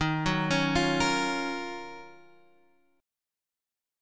D# Minor 9th